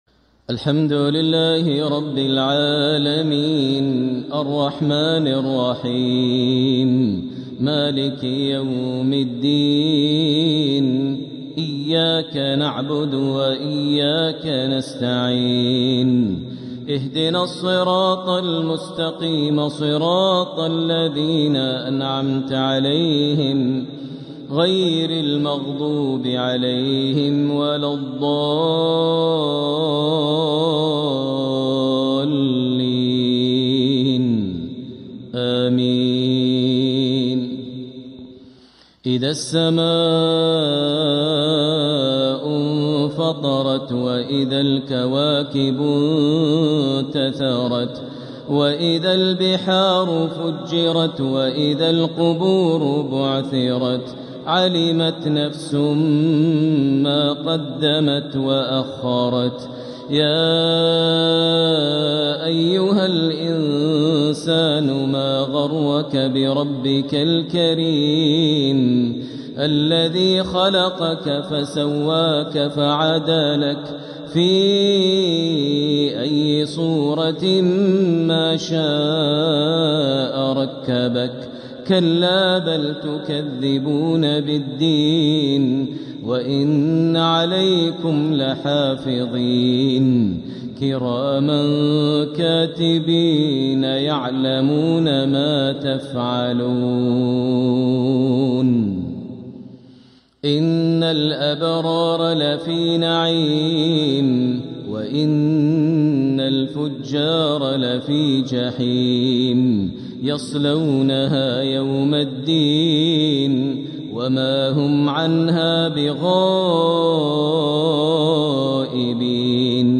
الإصدار القرآني المميز | لفروض شهر رجب لعام 1447هـ | لفضيلة الشيخ د. ماهر المعيقلي > سلسلة الإصدارات القرآنية للشيخ ماهر المعيقلي > الإصدارات الشهرية لتلاوات الحرم المكي 🕋 ( مميز ) > المزيد - تلاوات الحرمين